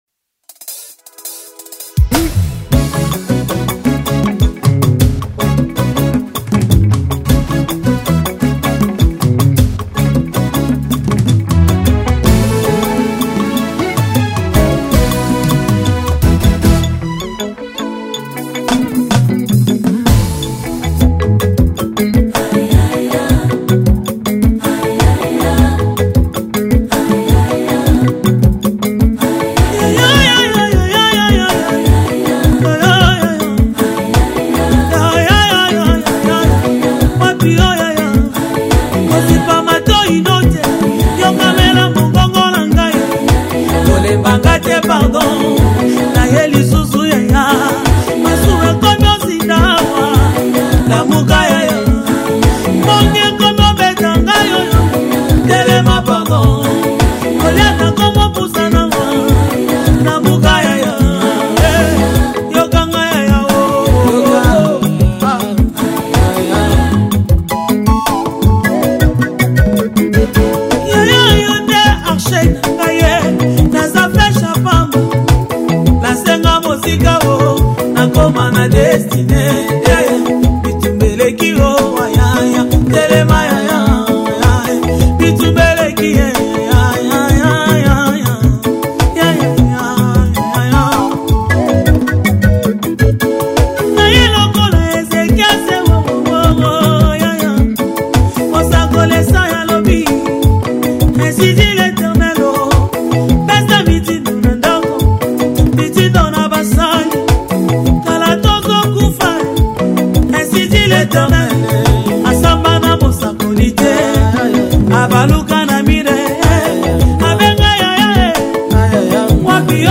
Gospel 2025